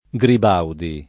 [ g rib # udi ]